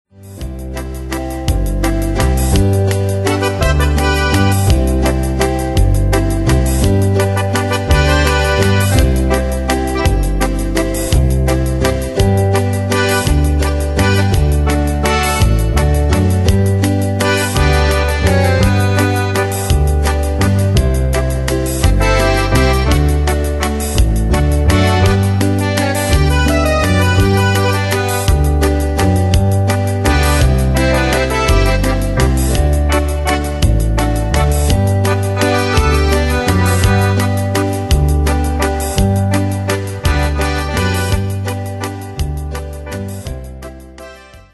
Demos Midi Audio
Style: Country Année/Year: 1946 Tempo: 168 Durée/Time: 2.32
Danse/Dance: Valse Cat Id.